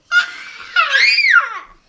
孩子们的尖叫声" 尖叫声4
描述：记录两个孩子在热铁皮屋顶上生产猫
Tag: 儿童 尖叫声 呼喊 呼喊 尖叫 孩子